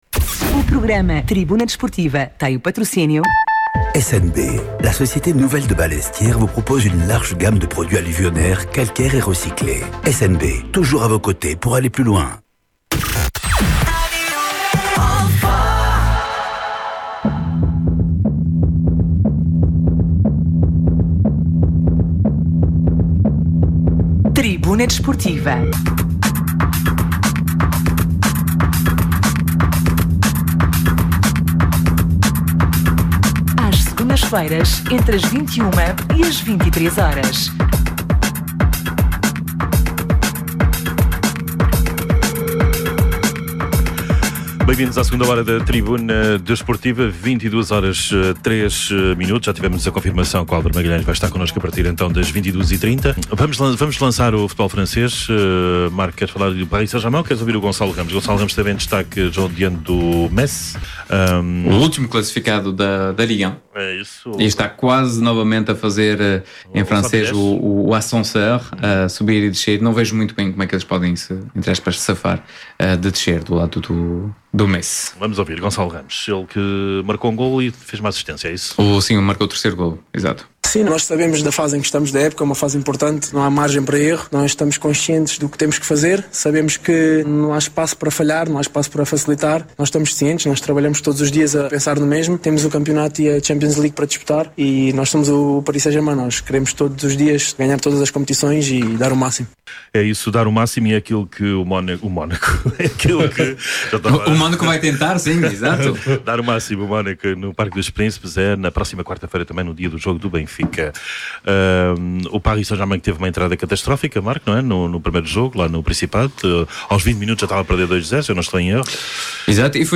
Atualidade Desportiva, Entrevistas, Comentários, Crónicas e Reportagens.